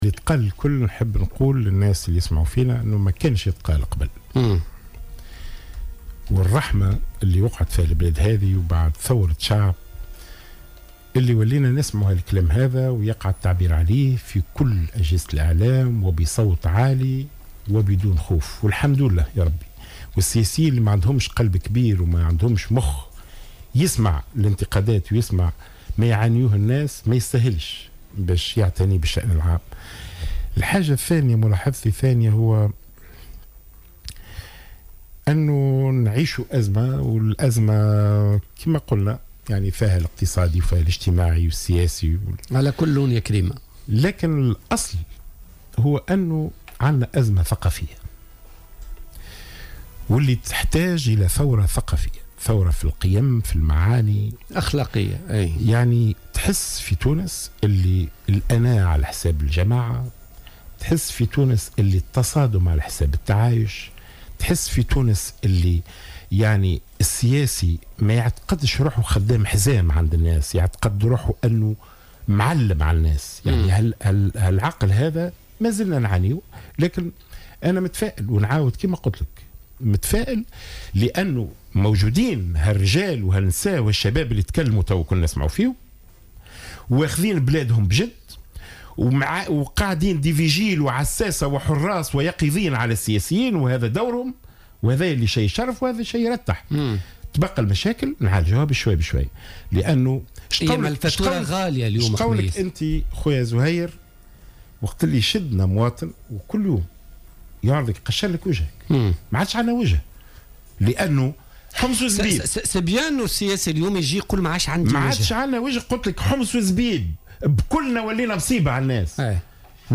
قال القيادي في حزب نداء تونس،خميس قسيلة ضيف برنامج "بوليتيكا" اليوم الثلاثاء إن تونس في حاجة إلى ثورة أخلاقية وثقافية.